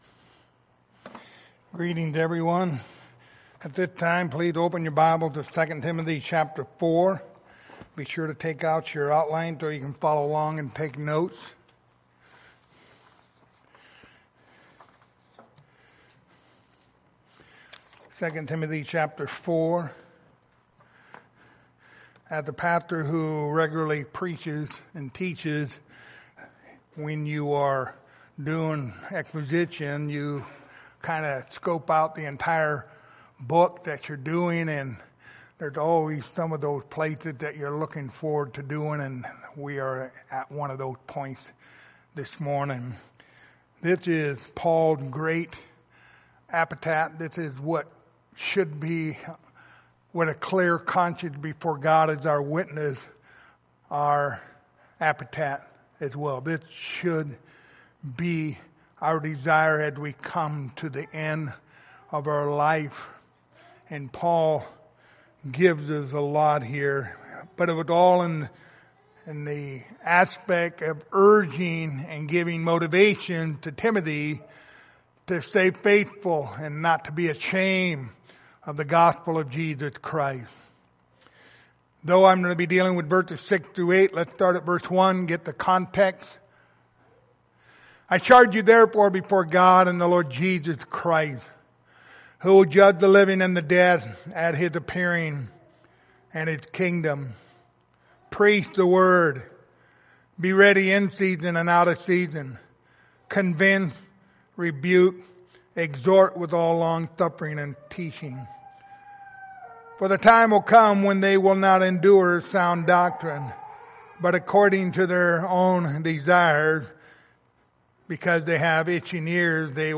Passage: 2 Timothy 4:6-8 Service Type: Sunday Morning